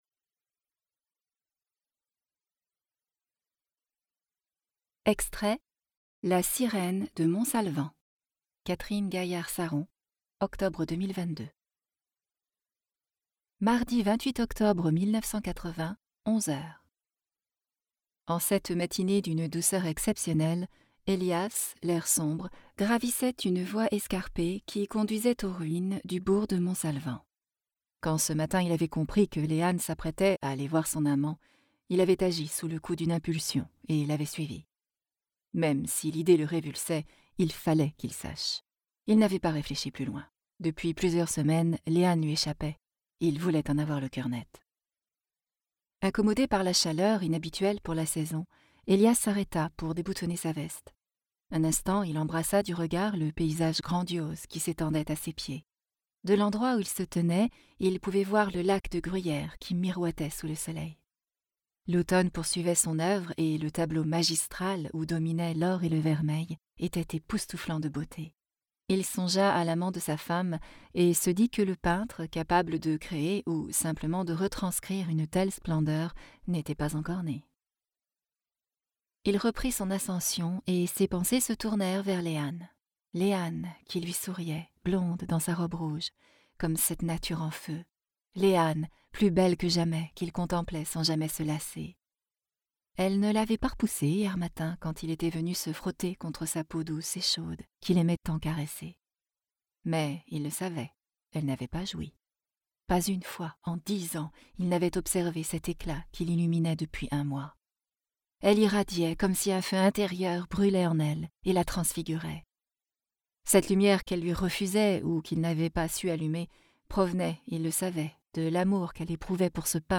Extraits voix off.